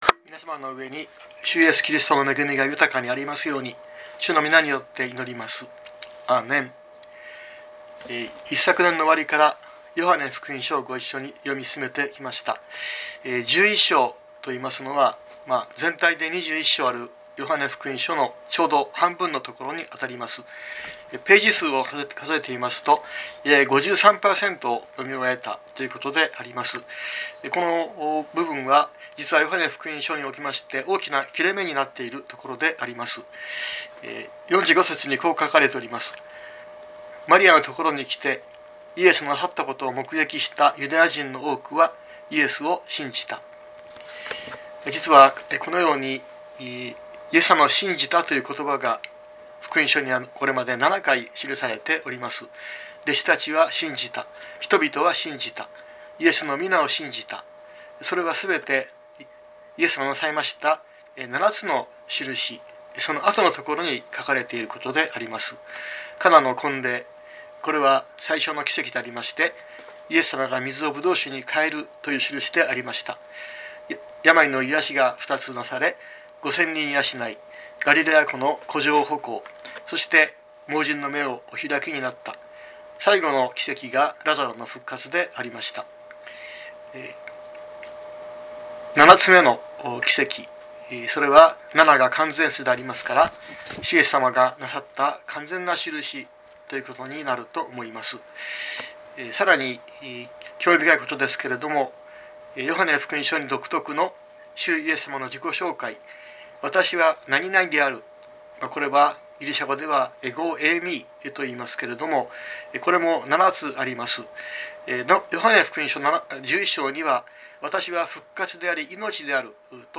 2023年05月21日朝の礼拝「代わりに死ぬイエス」熊本教会
熊本教会。説教アーカイブ。